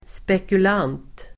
Uttal: [spekul'an:t]